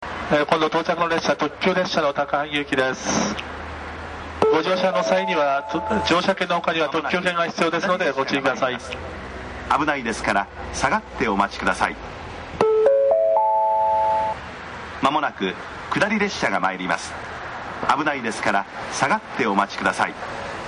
kairakuensekkin.mp3